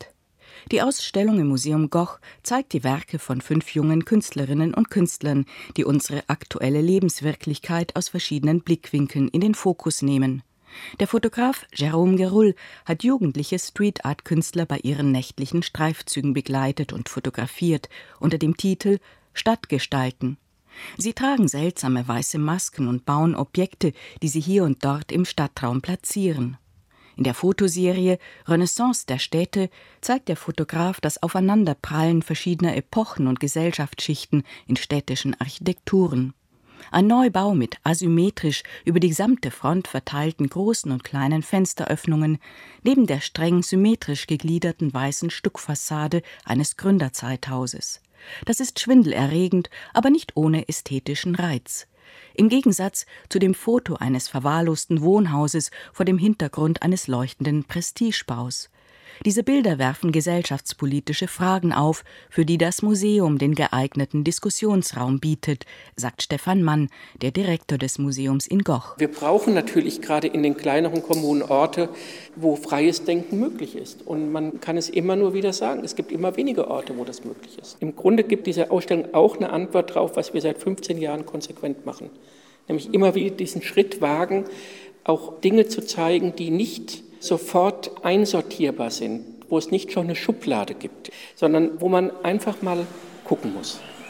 Radiobeitrag_WDR_Stadt_Gestalten_im_Museum_Goch.mp3